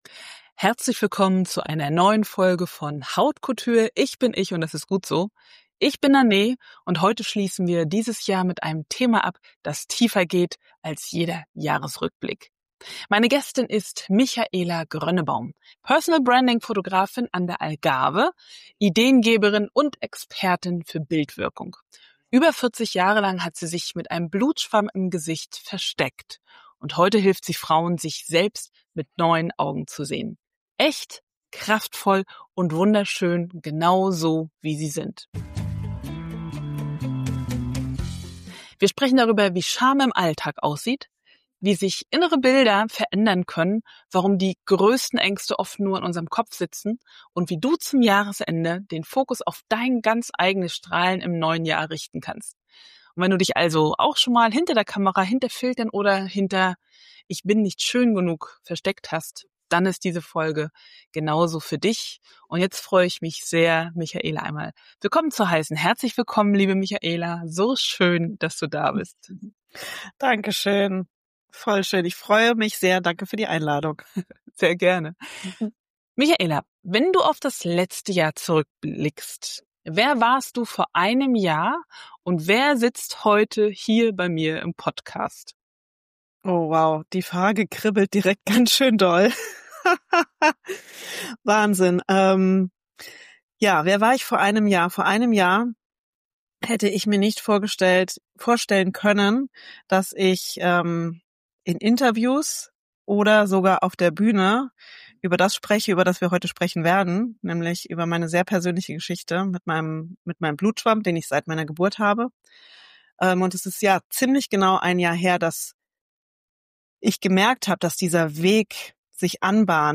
In dieser Jahresabschlussfolge spreche ich mit Personal-Branding-Fotografin